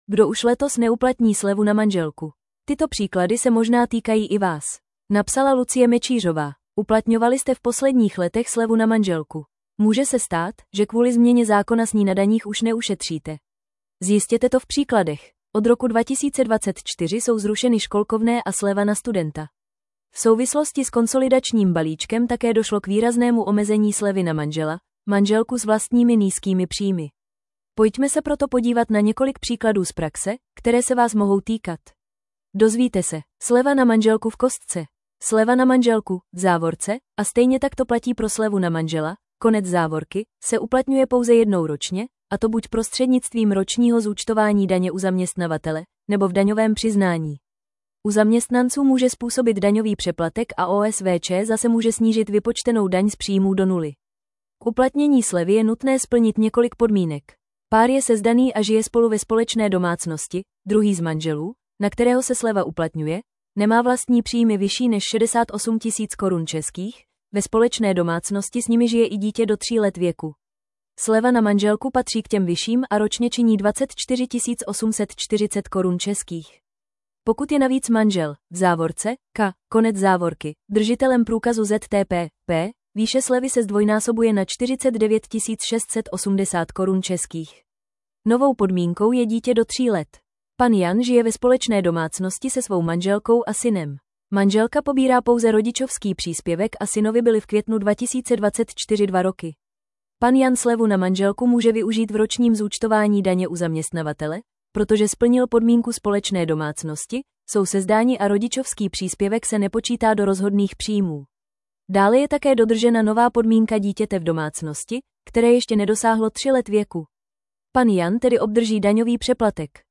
Tento článek pro vás načetl robotický hlas.